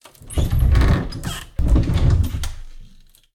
wardrobe1.ogg